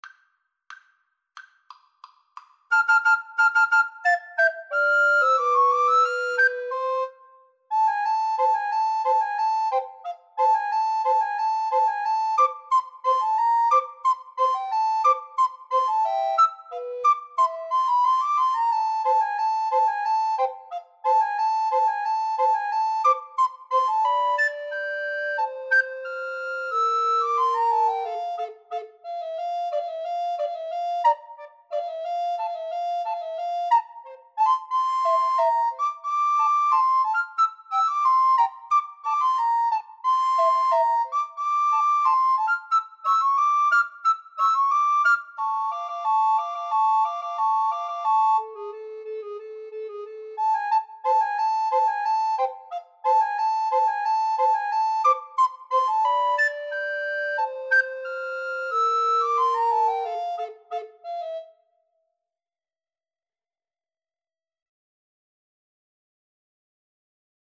Allegro =180 (View more music marked Allegro)